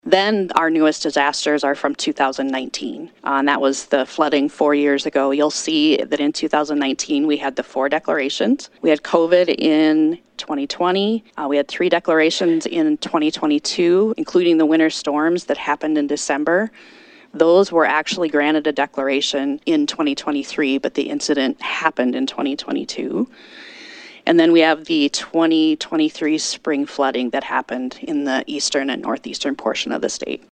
Turman says they also are cleaning up from several more recent disasters.